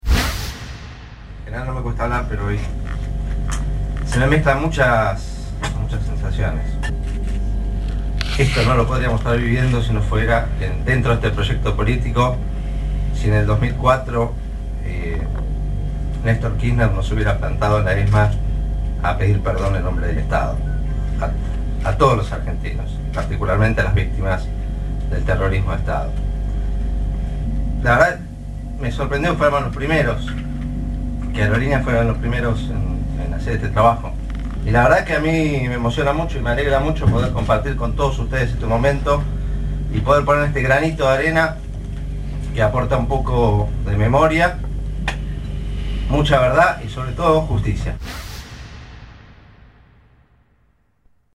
DDHH-AALa historia laboral de 4 trabajadores de Aerolíneas Argentinas y Austral fue reparada en sus legajos en un acto en el Ministerio de Trabajo organizado por la Comisión de Trabajo por la Reconstrucción de Nuestra Identidad, que contó con la presencia del presidente de la aerolínea de bandera, Mariano Recalde, y del secretario de Derechos Humanos, Martín Fresneda.
Aqui algunas palabras de Mariano Recalde cargadas de emoción